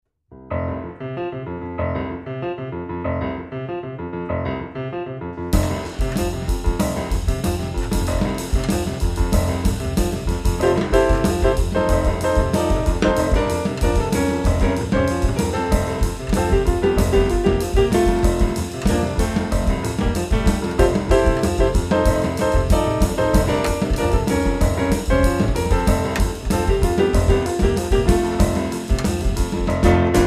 piano
thumb piano
batteria, percussioni
registrato ai Millennium Studio di Roma
assoli briosi e sorprendenti